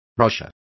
Complete with pronunciation of the translation of rashers.